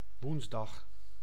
Ääntäminen
Ääntäminen France: IPA: [mɛʁ.kʁə.d͡zi] Tuntematon aksentti: IPA: /mɛʁ.kʁə.di/ Haettu sana löytyi näillä lähdekielillä: ranska Käännös Ääninäyte Substantiivit 1. woensdag {m} Muut/tuntemattomat 2. stik Suku: m .